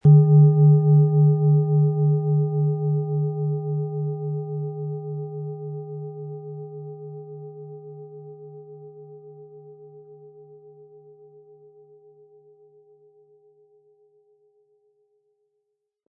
Tibetische Gelenk- und Universal-Klangschale, Ø 17,9 cm, 700-800 Gramm, mit Klöppel
Sanftes Anspielen mit dem gratis Klöppel zaubert aus Ihrer Schale berührende Klänge.